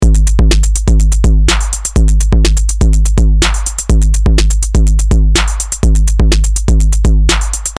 Here I have run the loop through the AR Mk1 overdrive, distorion and both together.
Voice overdrive: